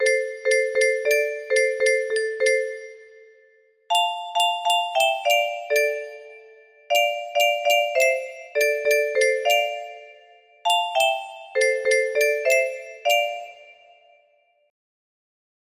Ver in die ou Kalahari 88 music box melody